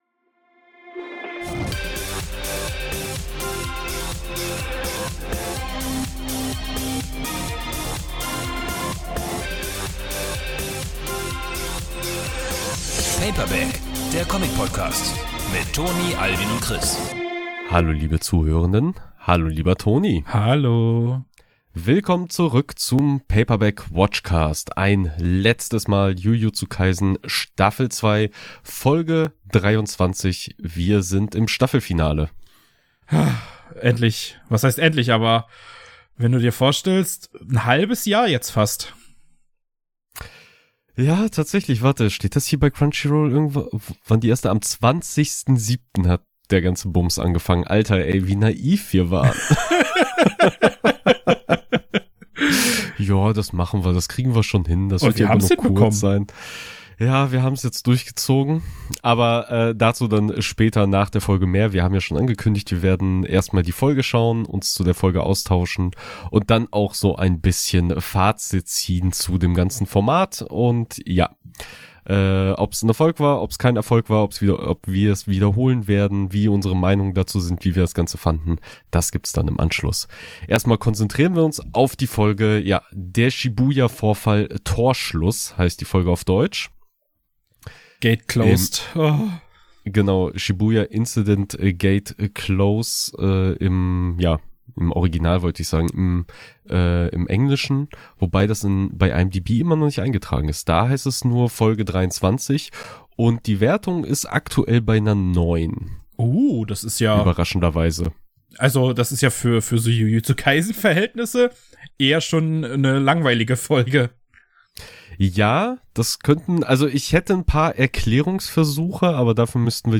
Paperback Watchcast – Jujutsu Kaisen S02E23 Audiokommentar ~ Paperback - Der Comic-Podcast Podcast
Paperback_Watchcast_---_Jujutsu_Kaisen_S02E23_Audiokommentar.mp3